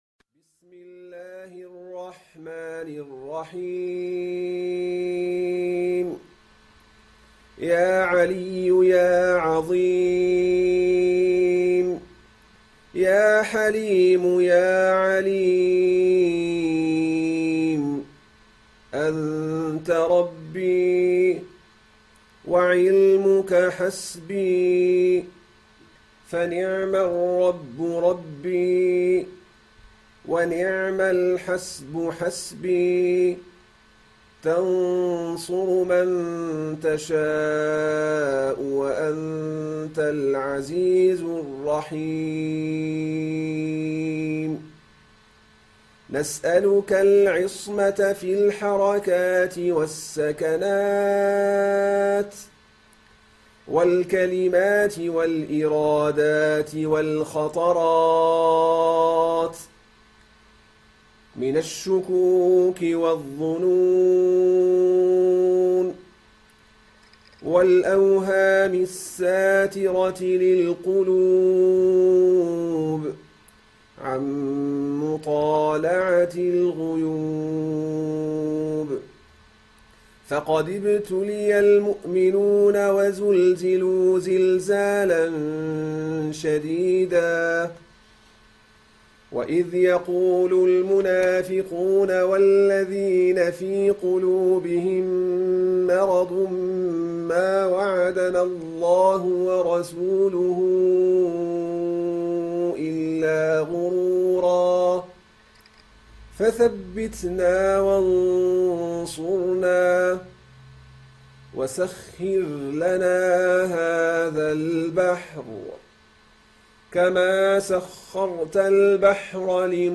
Hizb-al-Bahr_rec.Sh.Muhammad-alYaqoubi.mp3